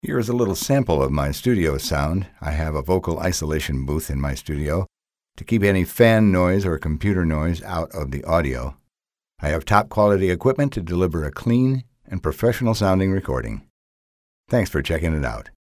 Male
Adult (30-50), Older Sound (50+)
A storyteller with a deep resonant voice.
Studio Quality Sample
A Clean Noiseless Recording